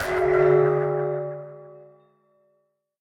Minecraft Version Minecraft Version snapshot Latest Release | Latest Snapshot snapshot / assets / minecraft / sounds / mob / guardian / curse.ogg Compare With Compare With Latest Release | Latest Snapshot
curse.ogg